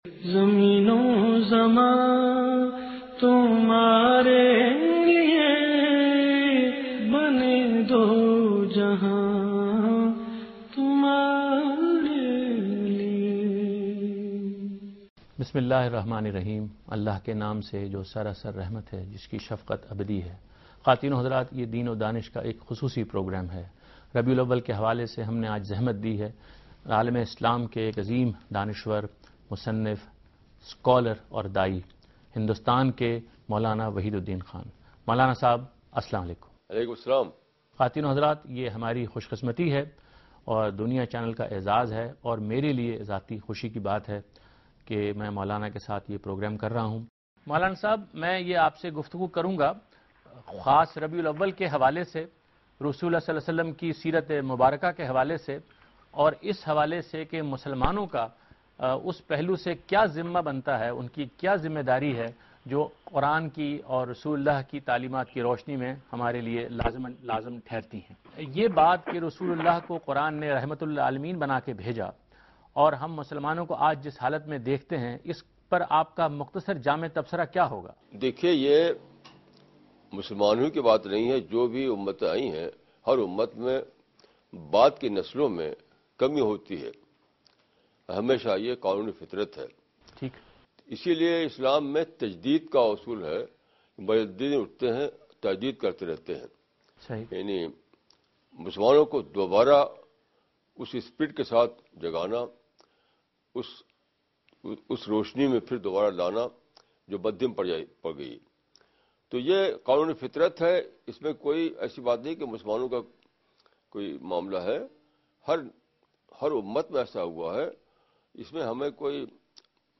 Category: TV Programs / Dunya News / Deen-o-Daanish / Questions_Answers /
دنیا ٹی وی کےاس پروگرام میں مولانہ وحید الدین خان "نبی اکرم کی زندگی اور ہماری ذمہ داریاں" کے متعلق ایک سوال کا جواب دے رہے ہیں